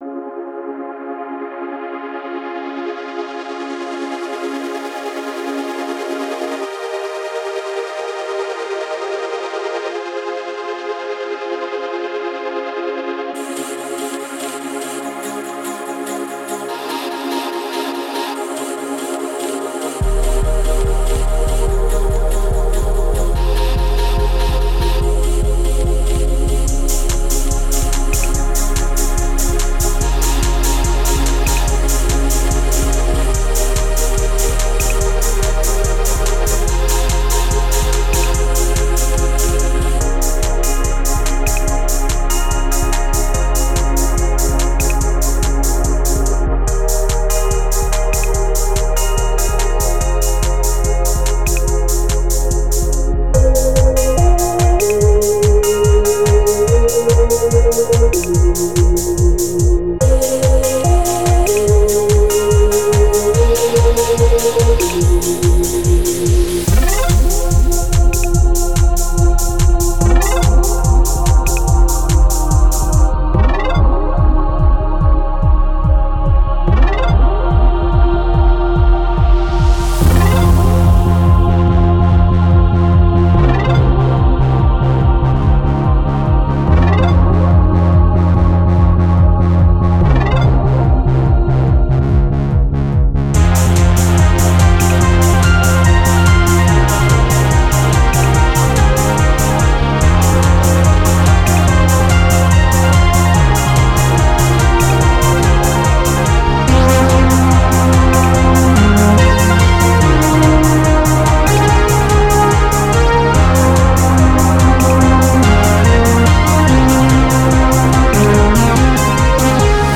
The beat drops away, and what follows is cinematic.
The mood is slower, but much bigger.